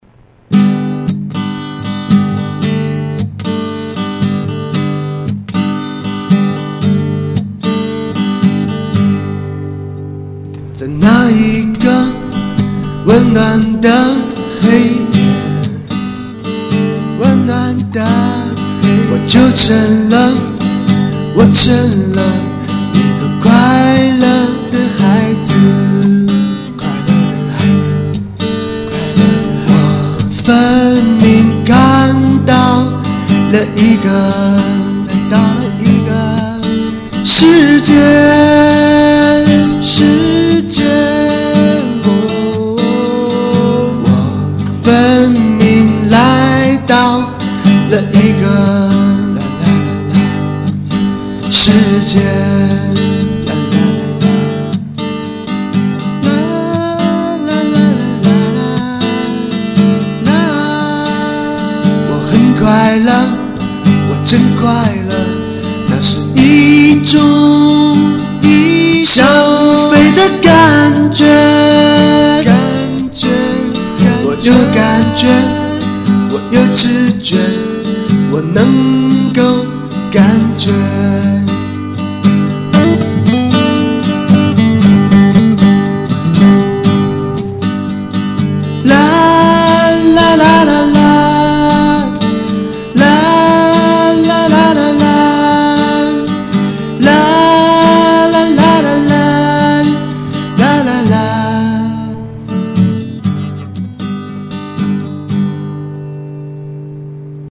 录音并不完美，不过，其实我喜欢的也不是完美，而是唱歌时那种最真实的心情……希望你有感觉